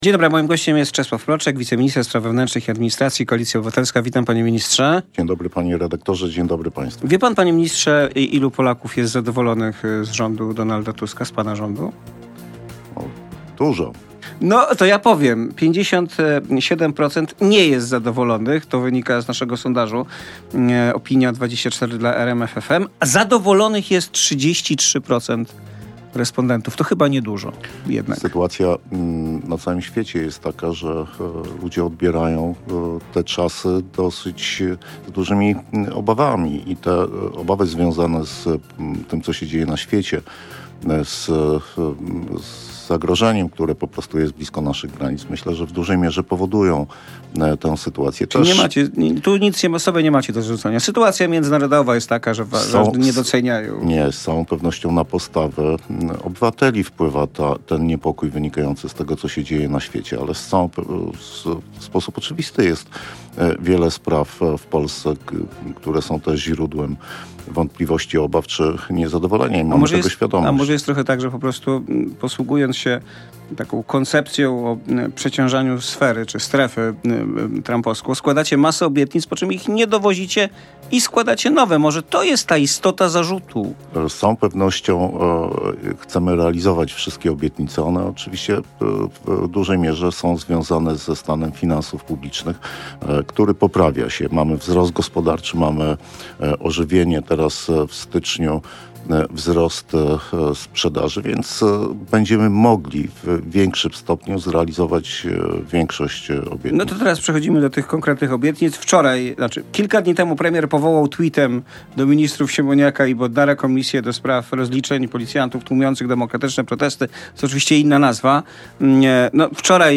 Na poranną publicystykę zaprasza Robert Mazurek.